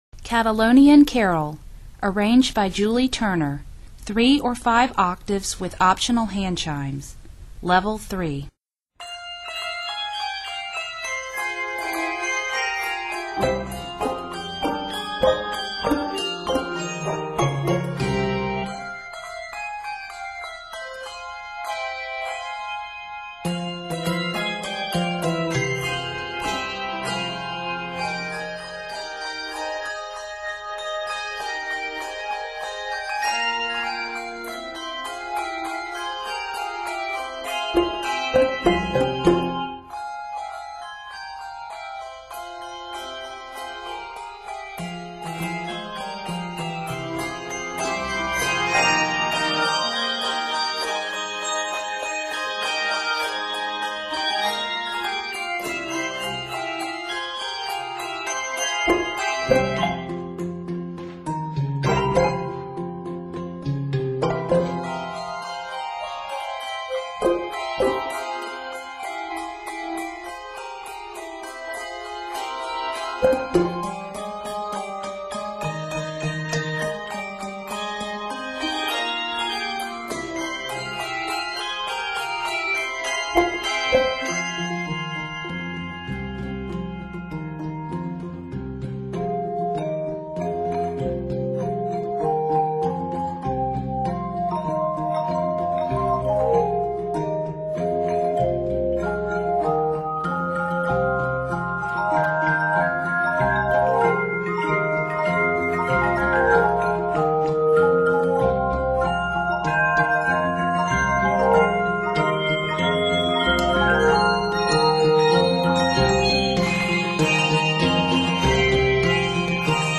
is set in f minor